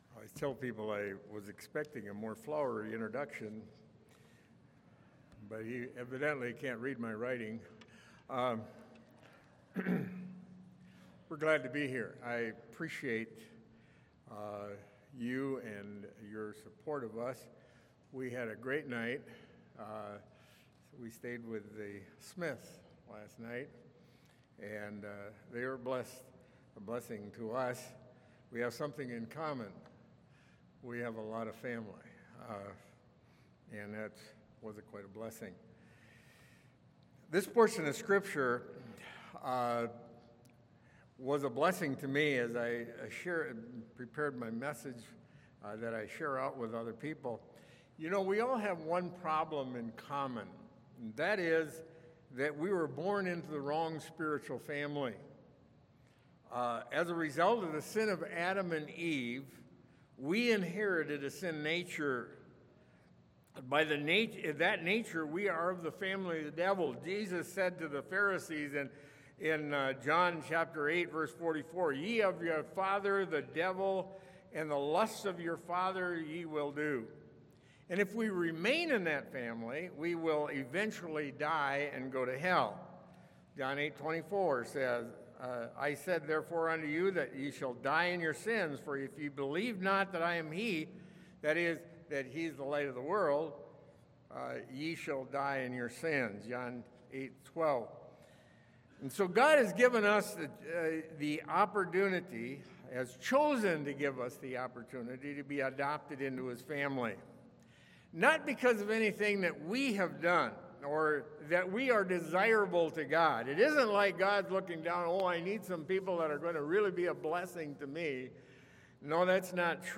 Ephesians 1:3-16 Service Type: Sunday Morning Why Adopt?